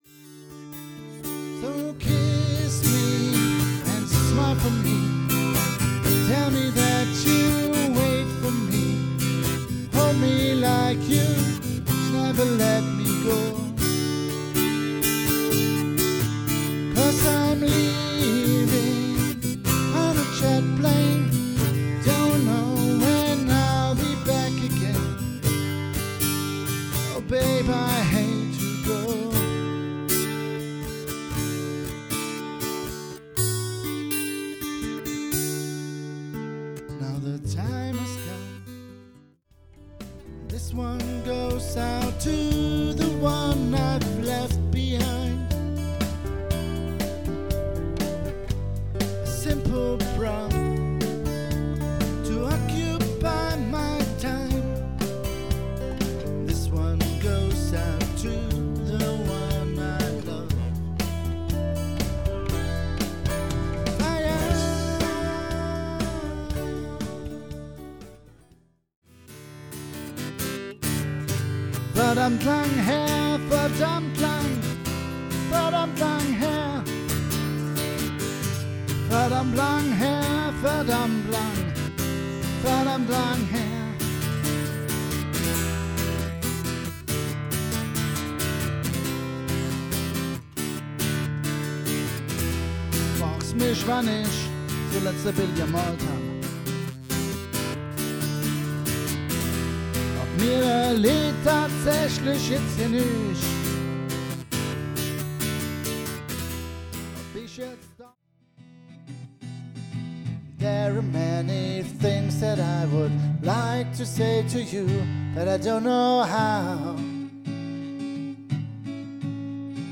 Unplugged - Pure Music